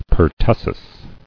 [per·tus·sis]